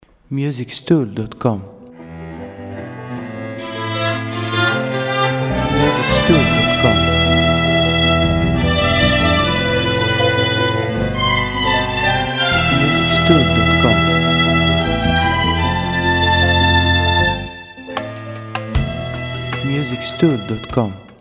• Type : Instrumental
• Bpm : Allegretto
• Genre : Oriental